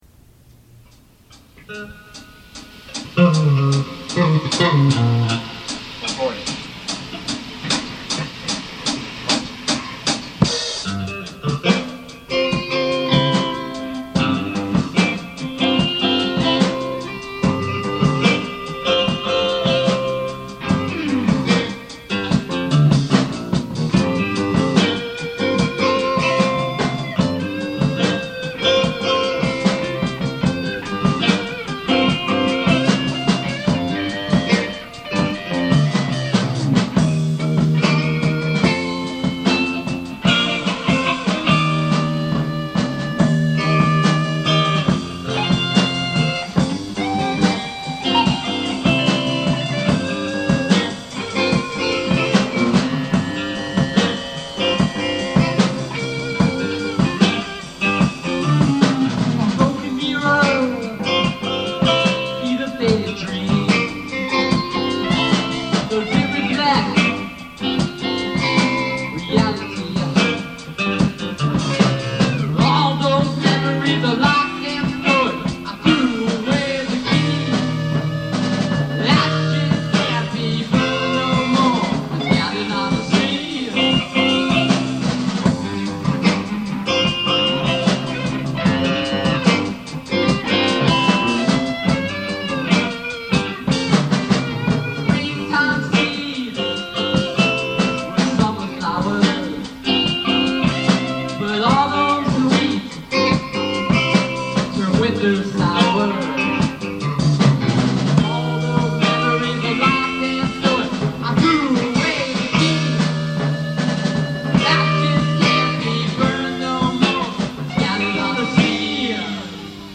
Click here to listen to The Headlights' rendition of the Song, Ashes Can't Be Burned, recorded over the course of a rehearsal in July, 1978.